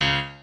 piano4_27.ogg